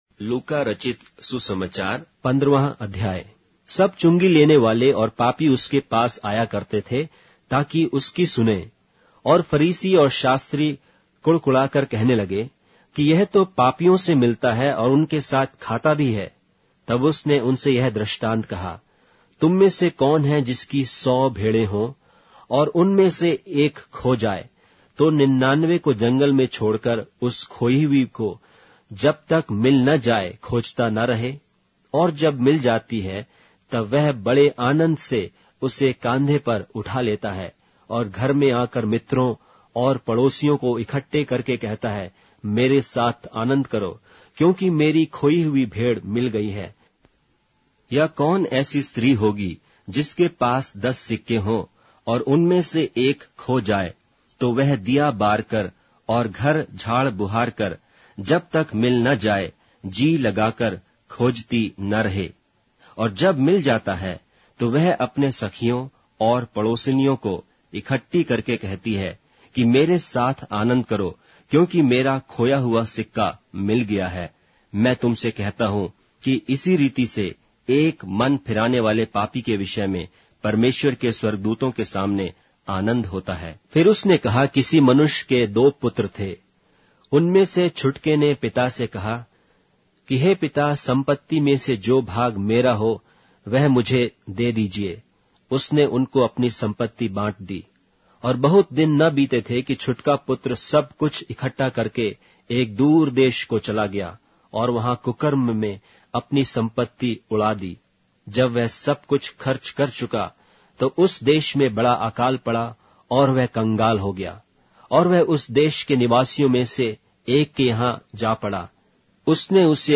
Hindi Audio Bible - Luke 3 in Orv bible version